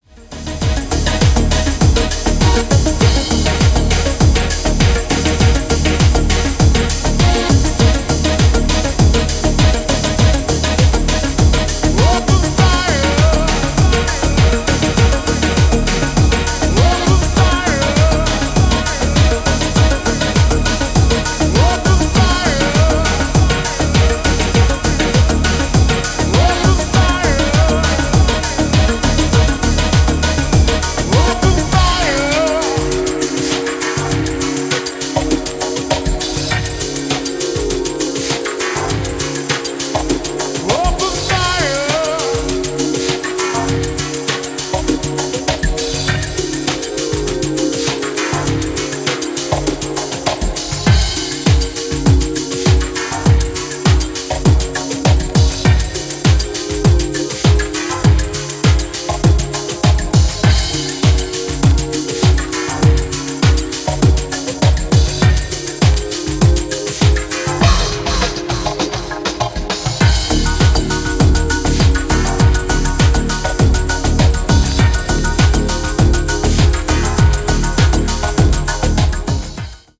重量級のスロー・バレアリック・ダンサー